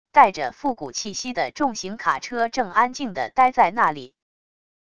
带着复古气息的重型卡车正安静地待在那里wav音频